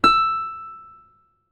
ZITHER E 4.wav